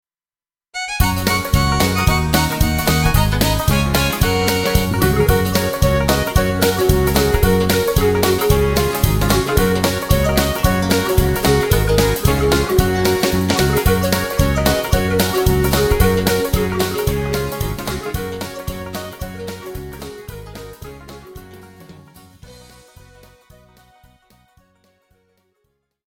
KARAOKE/FORMÁT: